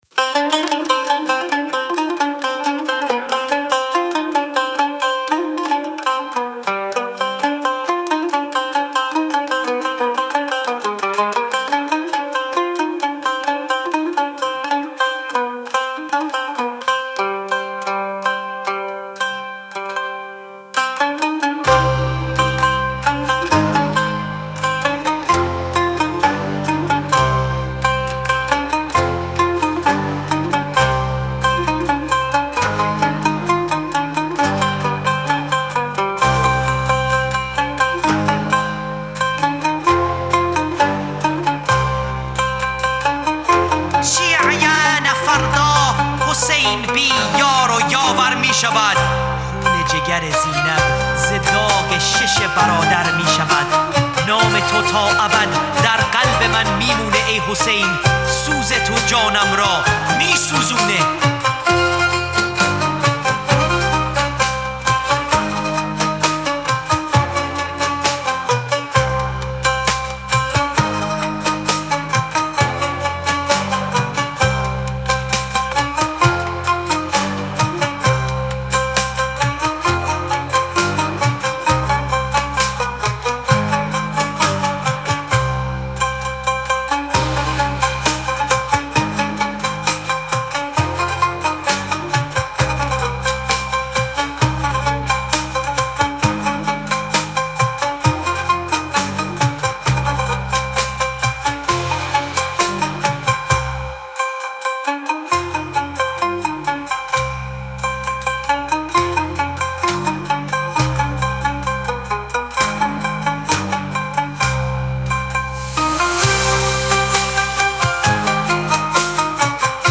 بریم دو نمونه مرثیه که تماما با هوش مصنوعی ساخته شده رو با هم گوش کنیم.